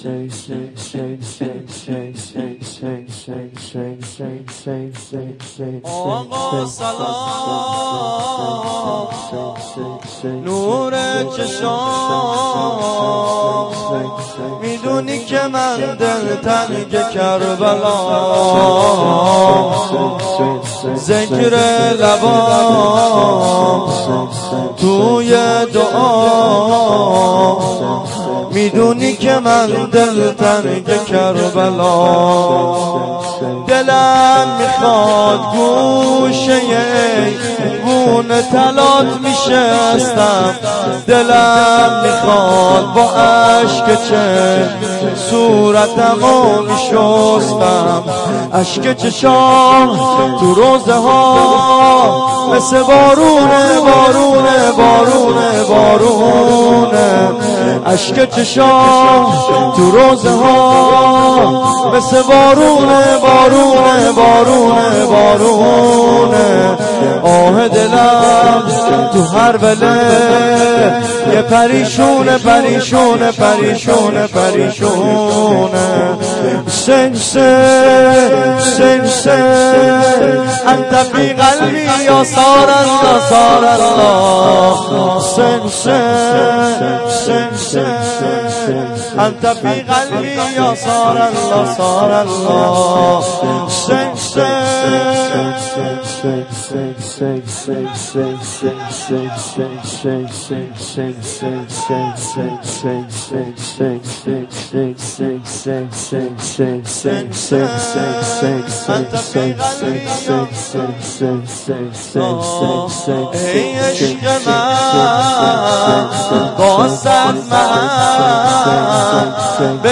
شور
جلسه هفتگی 26-9-93.mp3